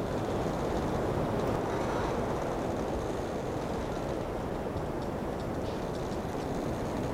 lightwindcreaks.wav